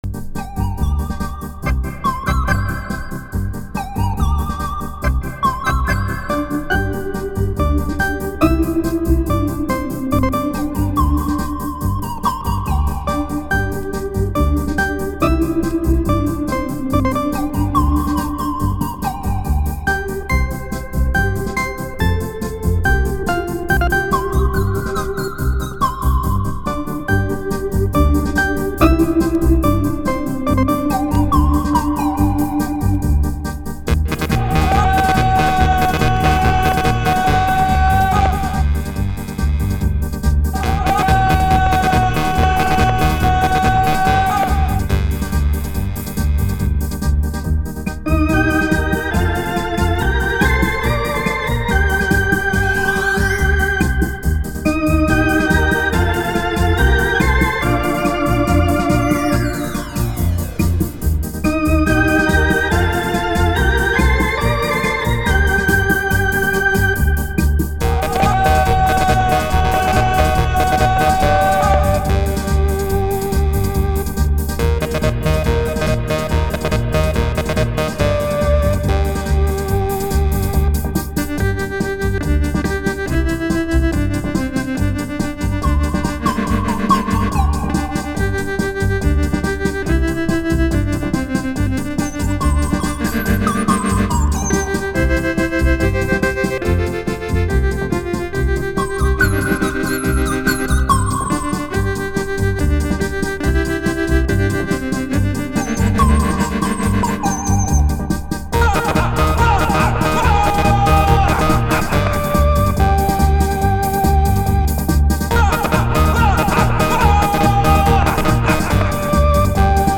что  гитарную пьесу обыгрывает в оргАнном варианте.